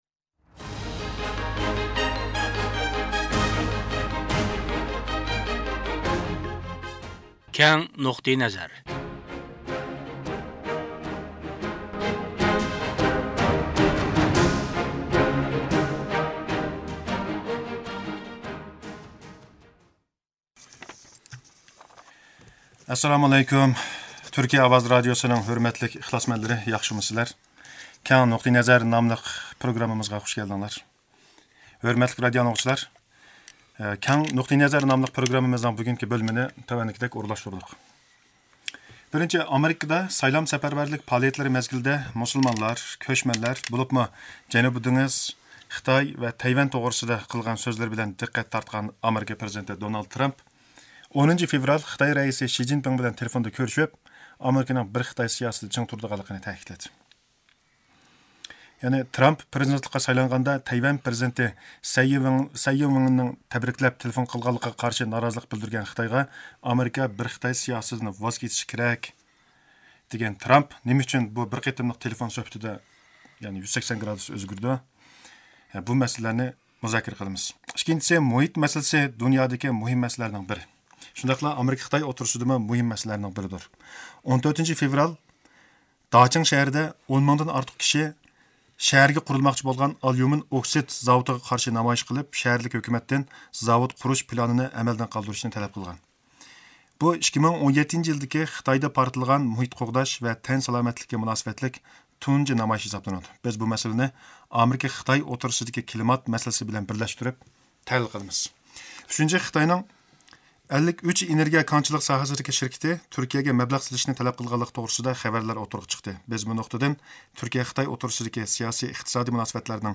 مەنبە: تۈركىيە رادىئو تىلىۋىزىيەسى خەۋرى